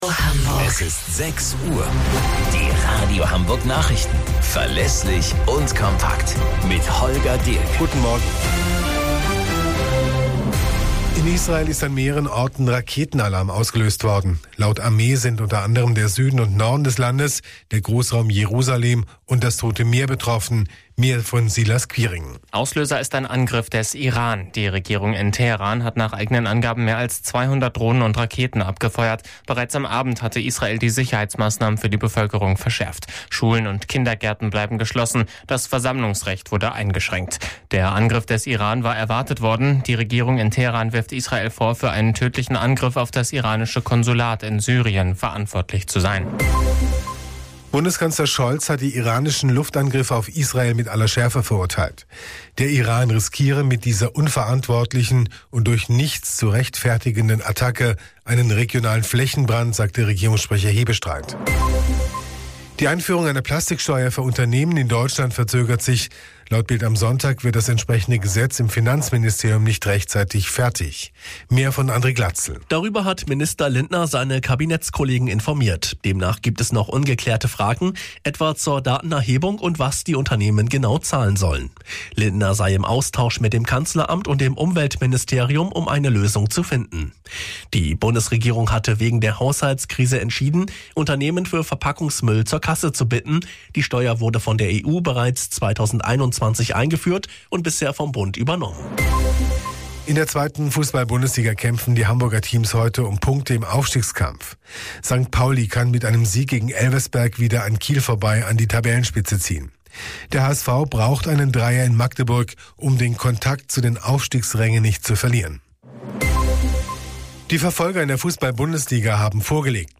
Radio Hamburg Nachrichten vom 14.04.2024 um 13 Uhr - 14.04.2024